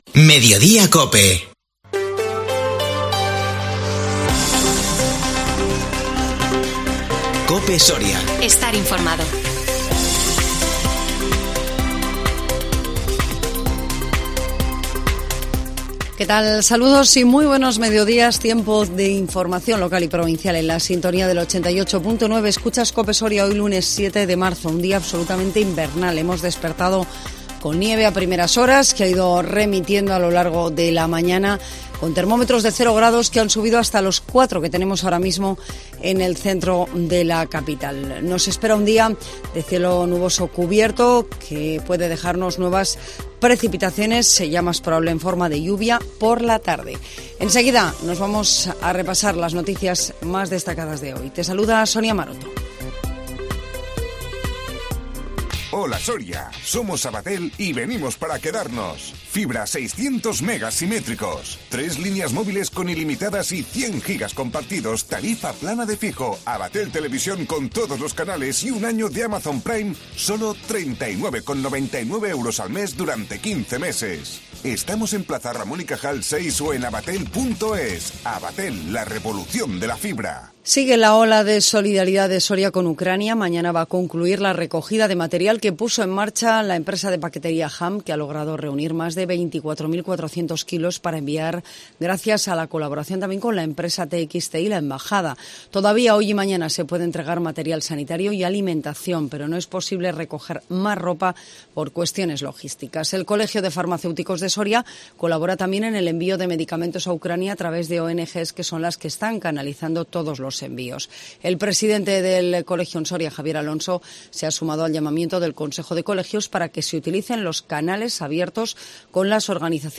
INFORMATIVO MEDIODÍA COPE SORIA 7 MARZO 2022